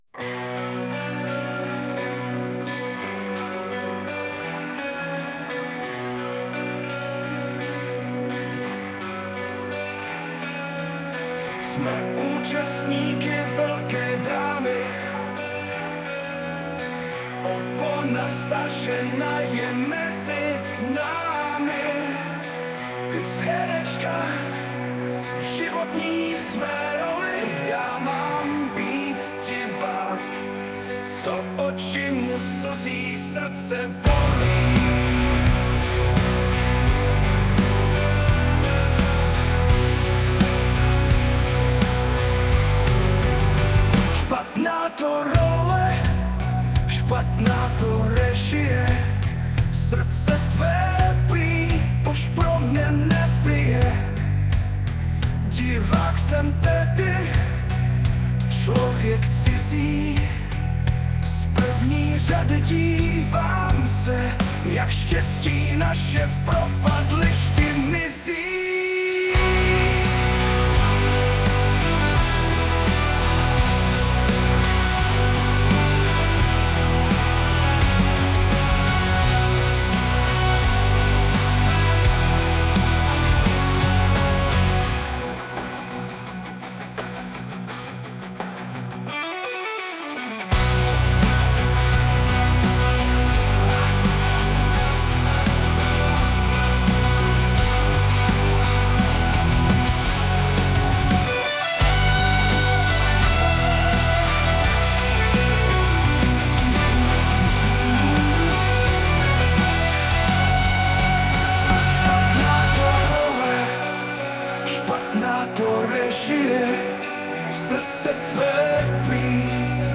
* hudba, zpěv: AI